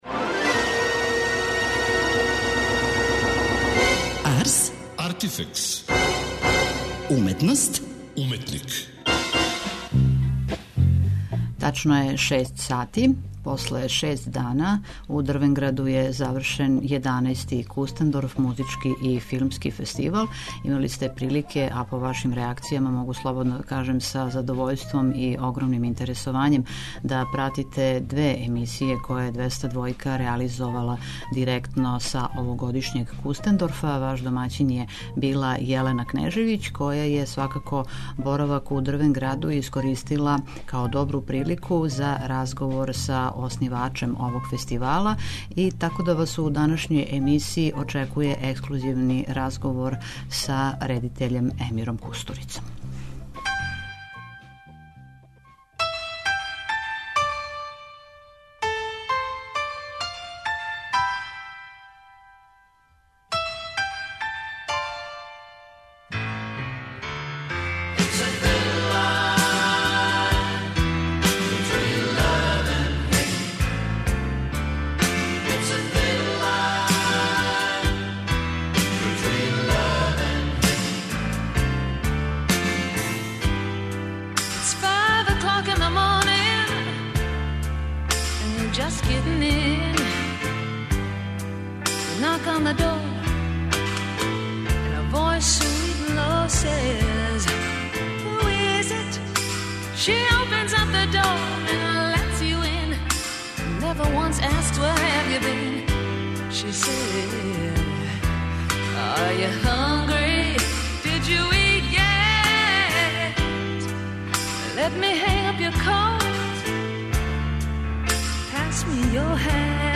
Интервју: Емир Кустурица